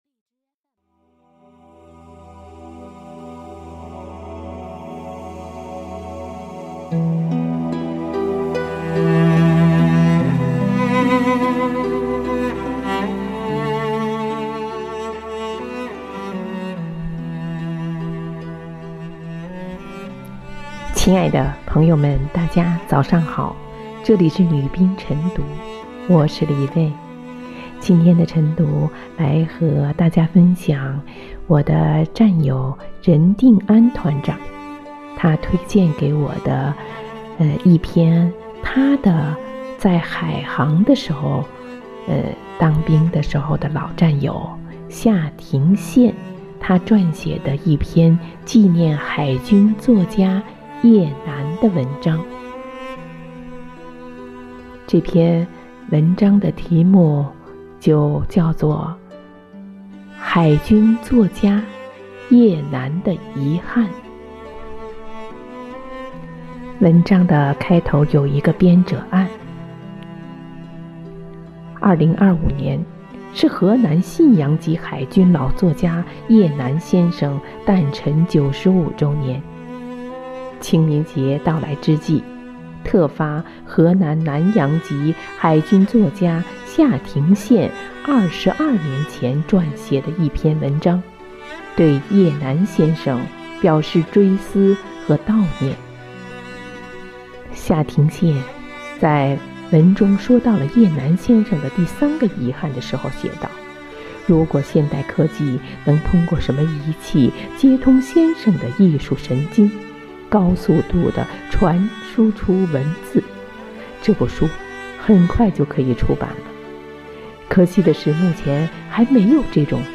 每日《女兵诵读》海军作家叶楠的遗憾